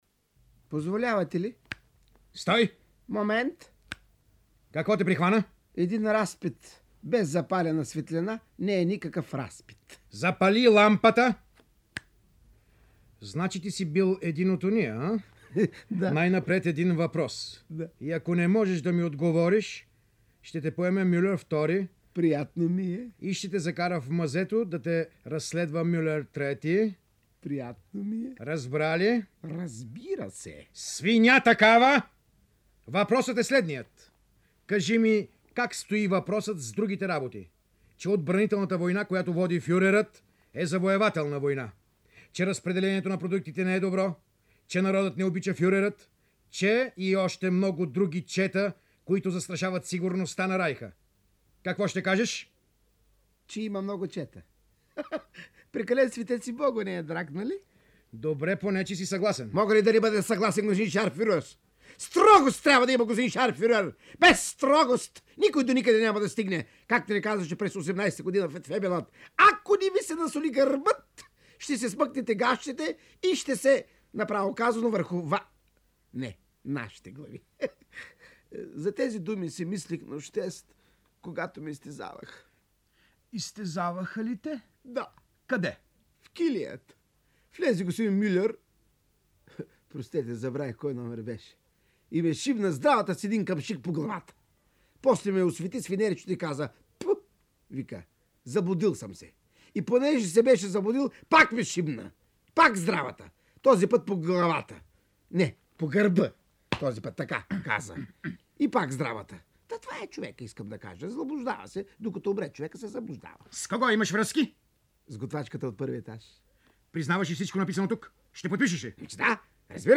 Димитър Манчев в ролята на Булингер, откъс из „Швейк през Втората световна война” от Бертолд Брехт – участват Георги Калоянчев и Васил Попов.